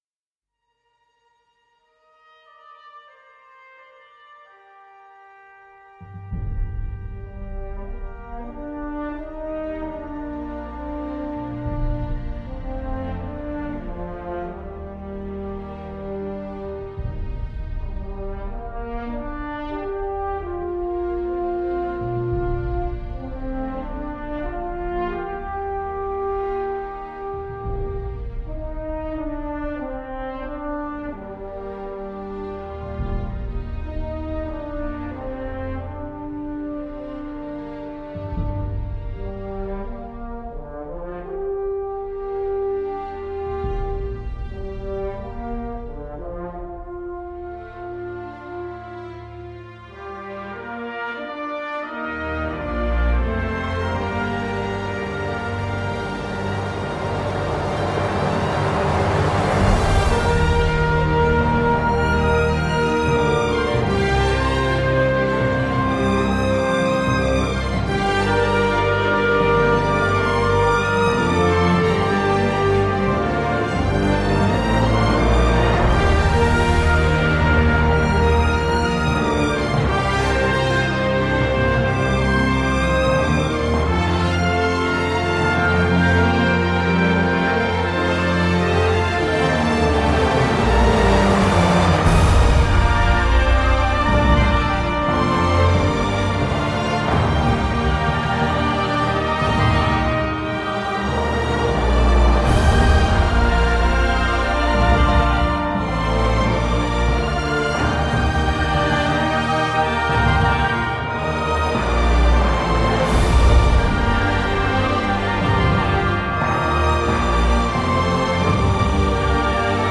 beau thème avec cuivres solennels et bois aériens